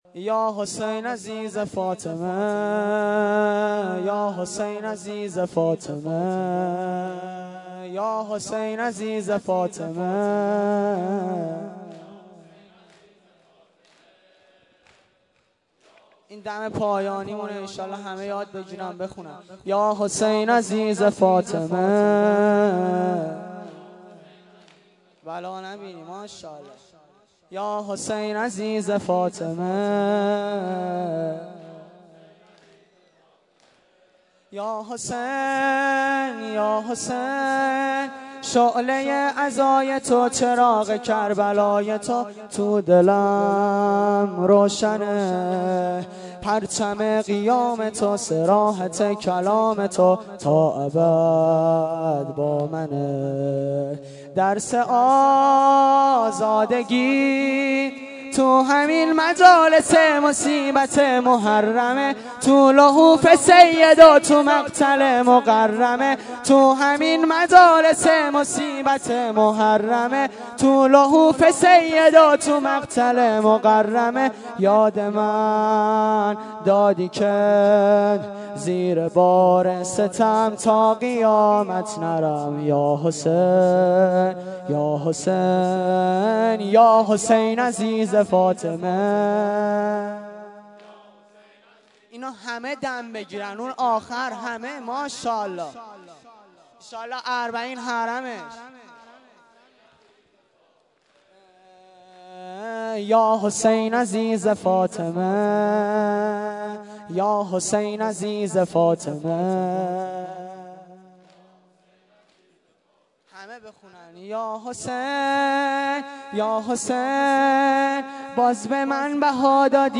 فایل های صوتی شب اول محرم1398
شعرپایانی